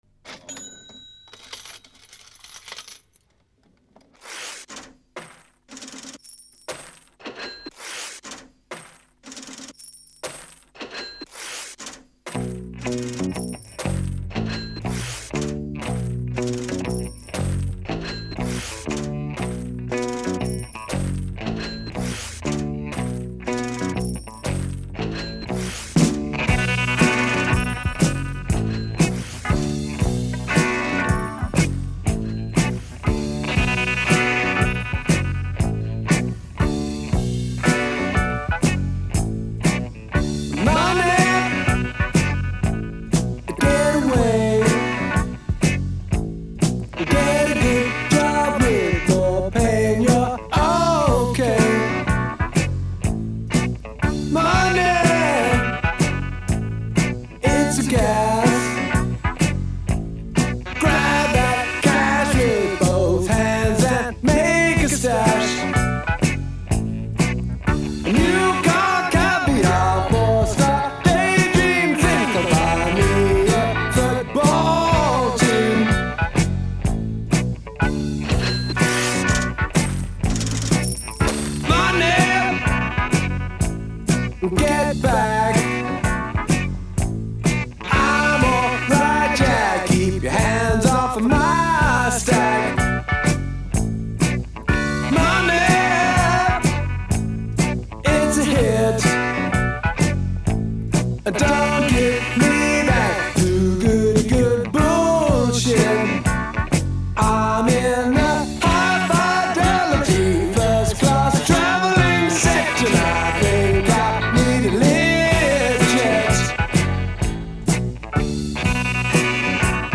rock
рок-музыка